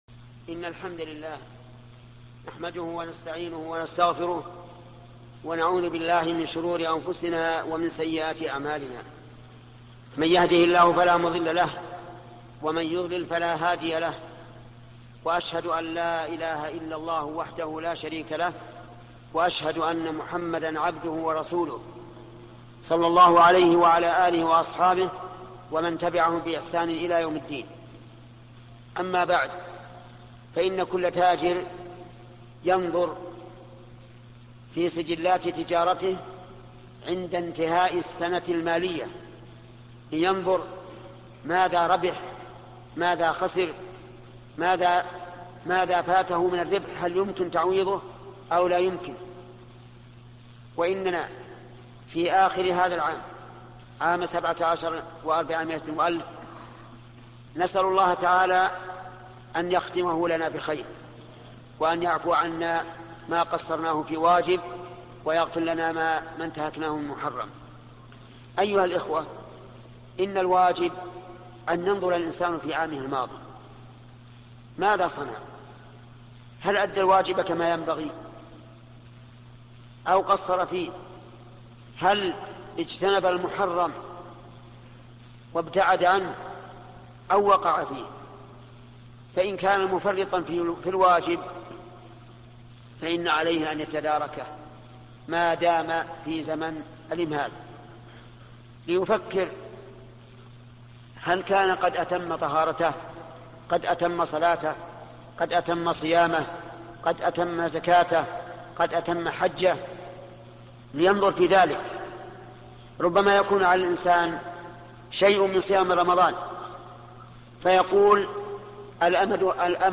خطبة جمعة - الشيخ ابن عثيمين - مشروع كبار العلماء